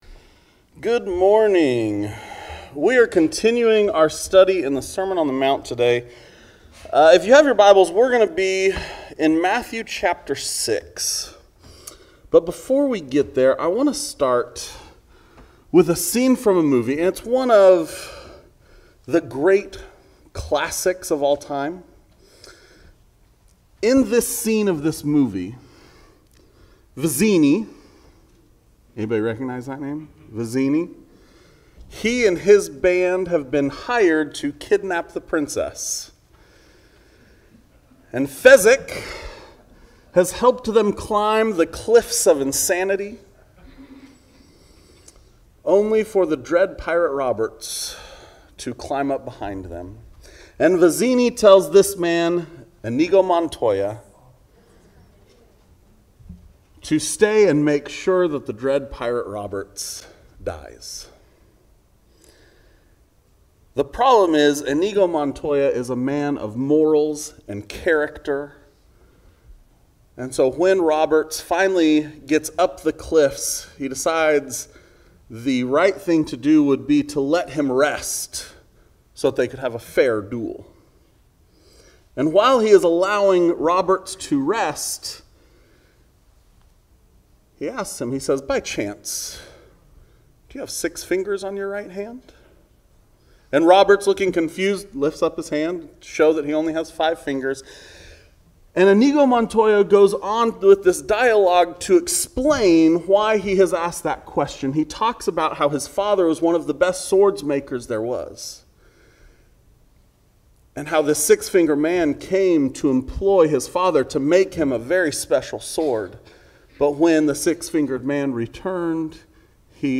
Sermons | Oregon City Church of Christ